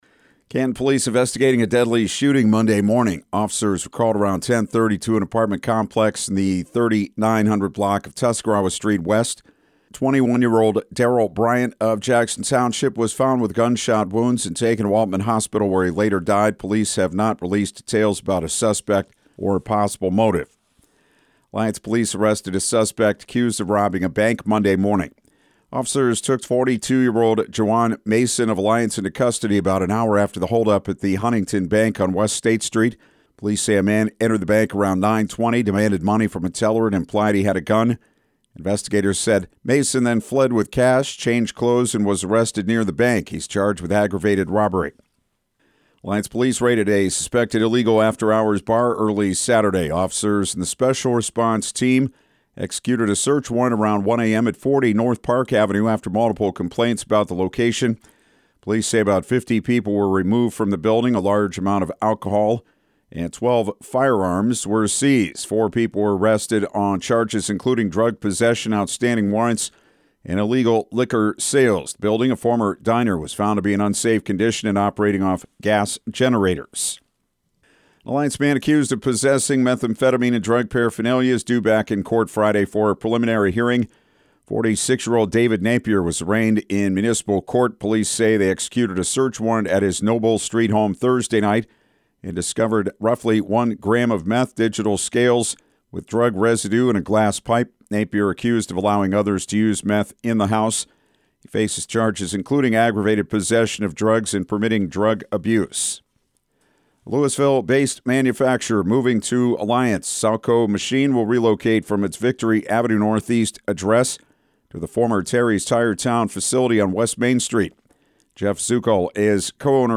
6am-news-04.mp3